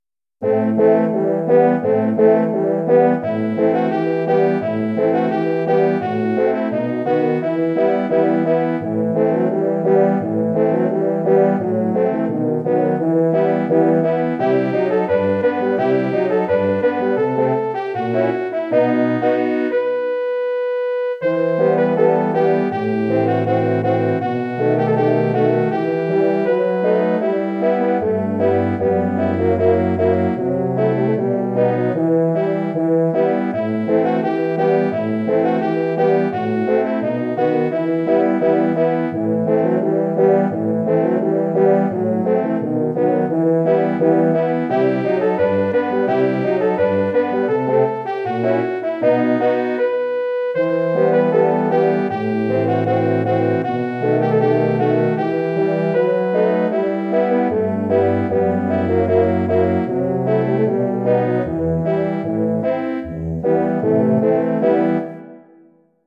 Arrangement / Hornquartett
Bearbeitung für Hornquartett
Besetzung: 4 Hörner
Arrangement for horn quartet
Instrumentation: 4 horns